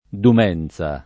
[ dum $ n Z a ]